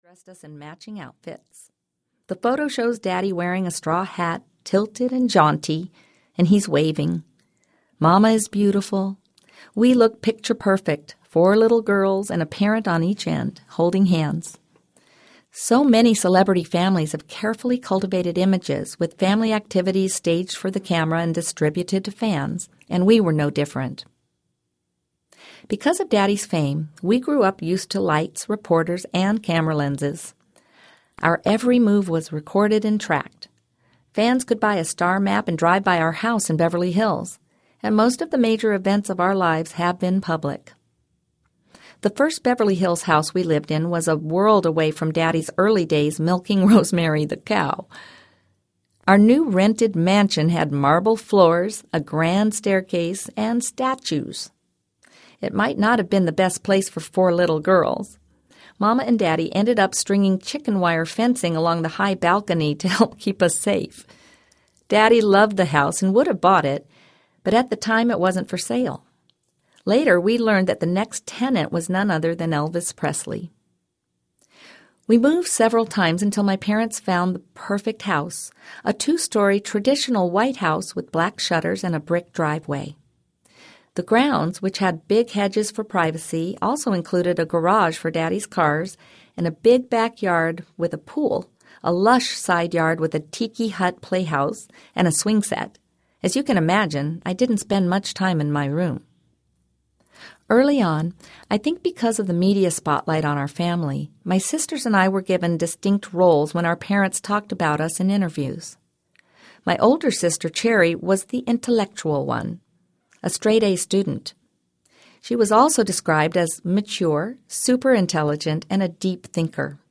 Heaven Hears Audiobook